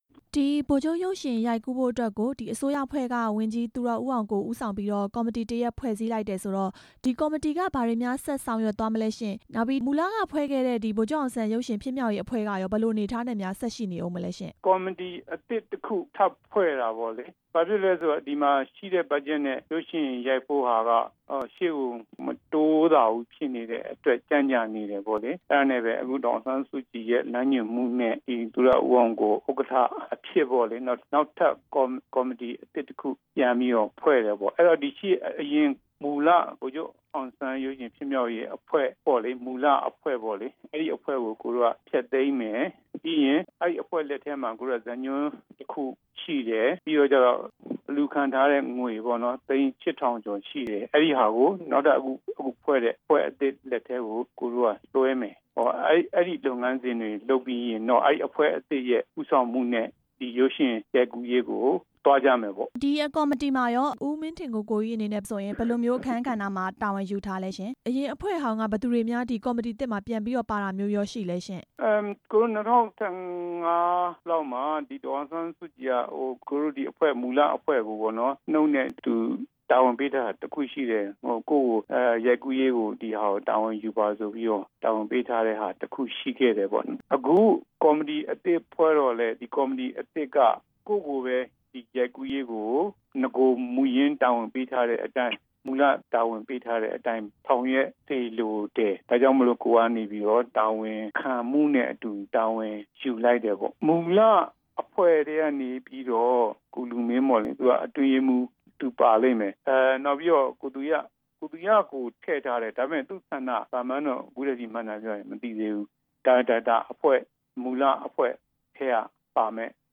ဗိုလ်ချုပ်ရုပ်ရှင် အကြောင်း ဒါရိုက်တာ မင်းထင်ကိုကိုကြီး ကို မေးမြန်းချက်